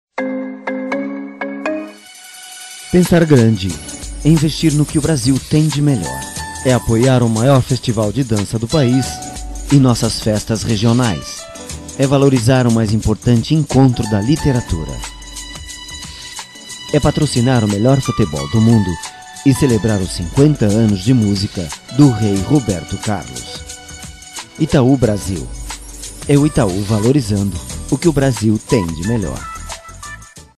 Actor and dubber of film or phrases in Portuguese from Brazil and Portuguese from Portugal.
Sprechprobe: Werbung (Muttersprache):